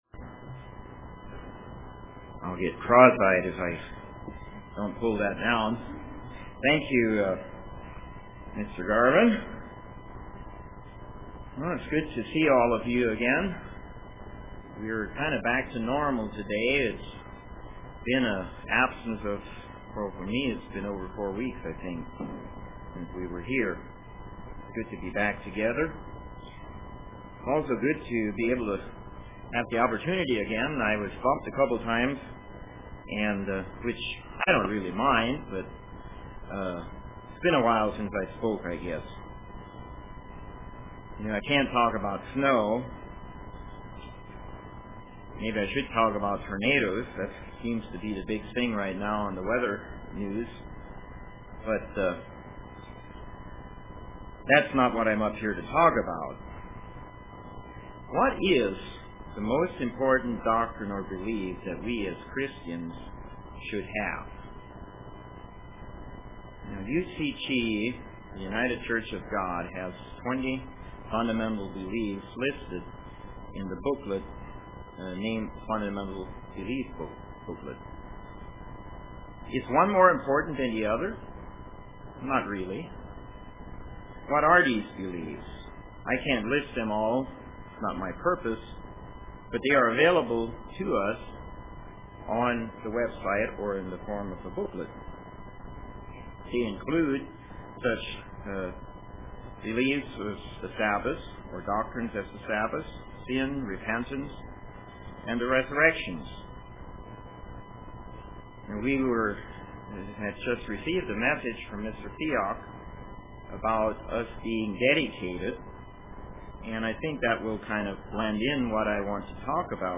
When who and why UCG Sermon Studying the bible?